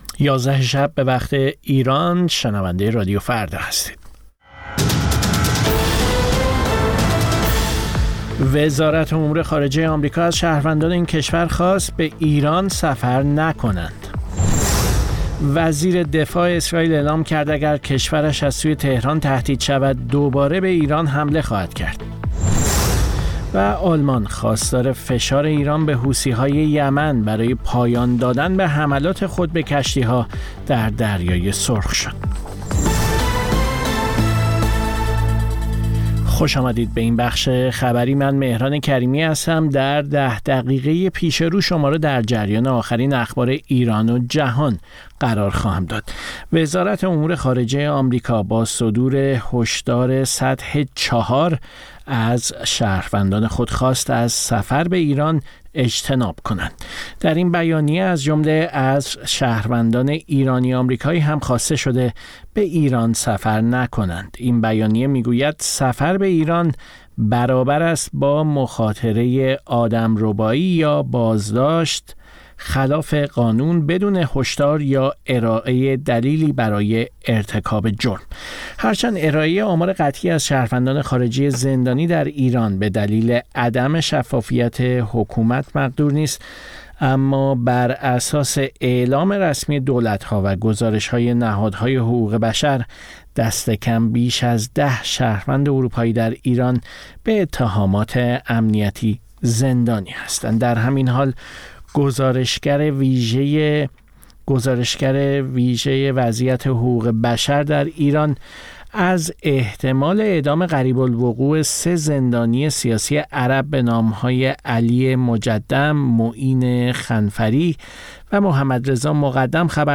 سرخط خبرها ۲۳:۰۰